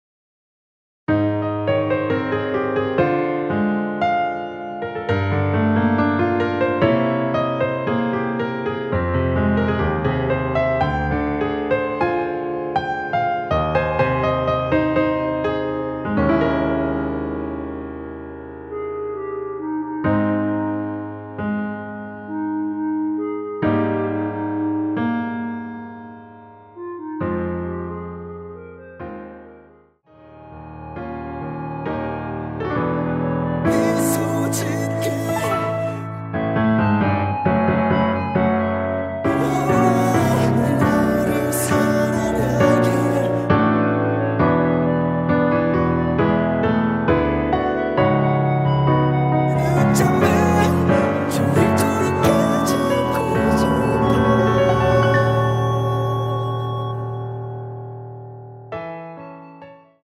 원키 멜로디와 코러스가 포함된 MR입니다.(미리듣기 참조)
Ab
앞부분30초, 뒷부분30초씩 편집해서 올려 드리고 있습니다.
중간에 음이 끈어지고 다시 나오는 이유는